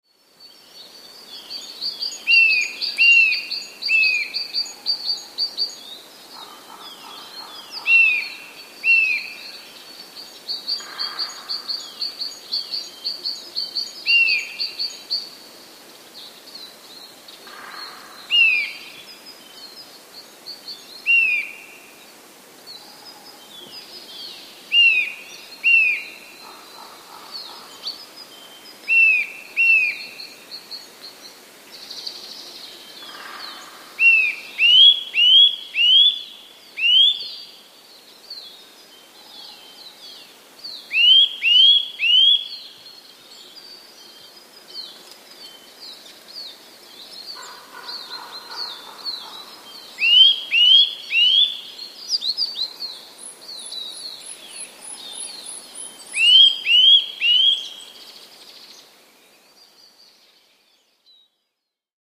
Kowalik - Sitta europaeus
Kowalik, po serii gwizdów zawiniętych w dół,
potrafi płynnie przejść do serii gwizdów zawiniętych w górę.